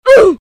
Catégorie: Bruitages